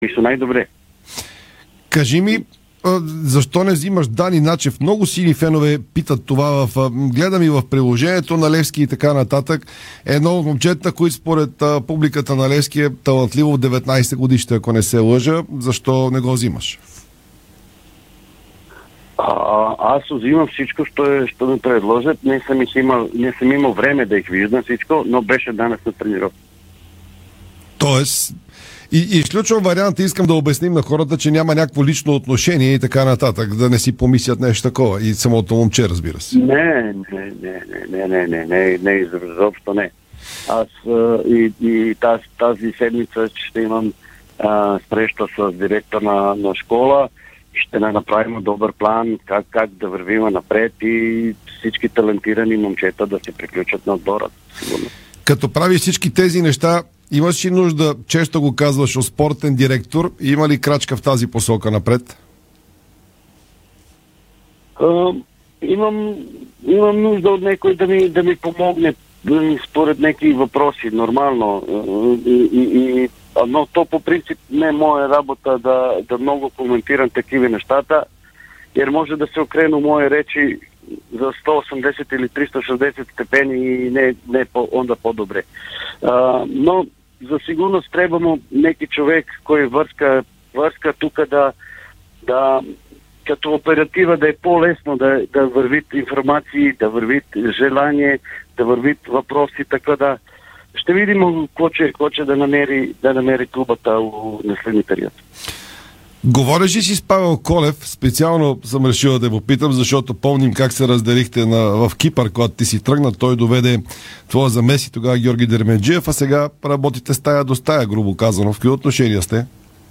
Старши треньорът на Левски Славиша Стоянович говори по различни теми в ефира на Дарик радио. Той направи интересно сравнение свързано с йерархията в отбора.